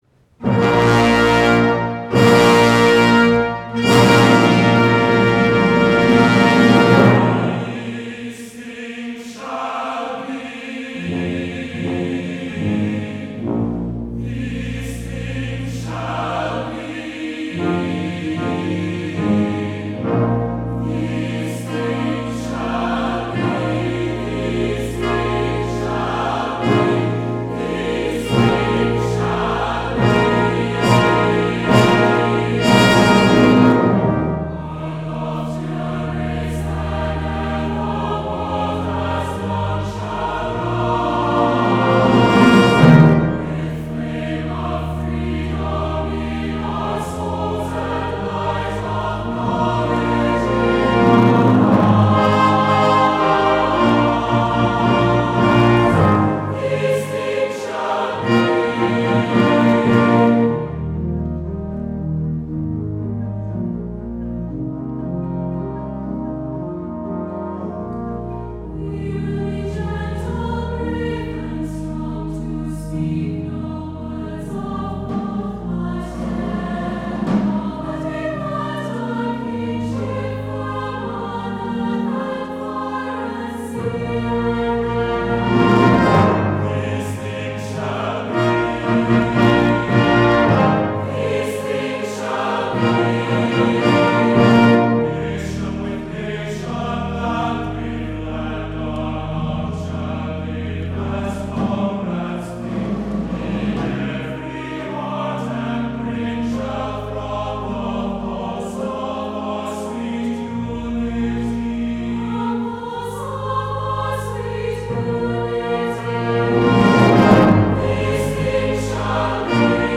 SATB, brass, and organ version